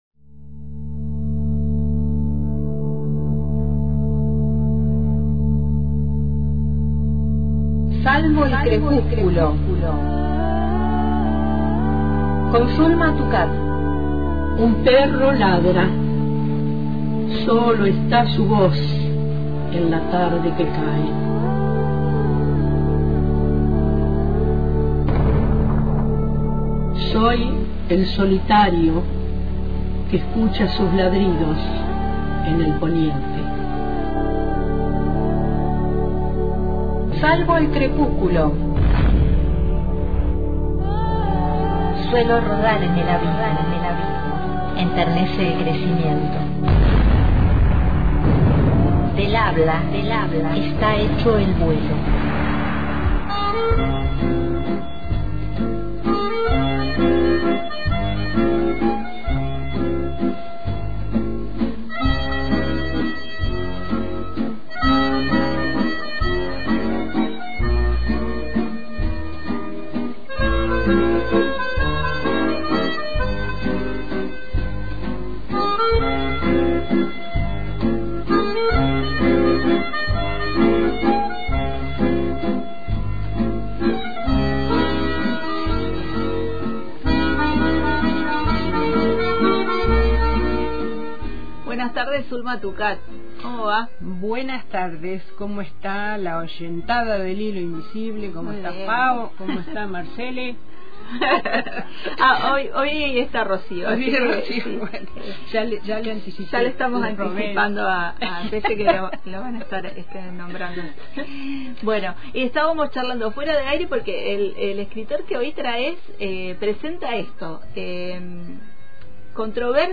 Repasamos su obra y los hechos culturales e históricos que retrata a través de su novela policial y la encarnación de sus personajes. También esuchamos en la propia voz del escritor, su visión acerca de su escritura y la manera de retratar lo social a través de ella.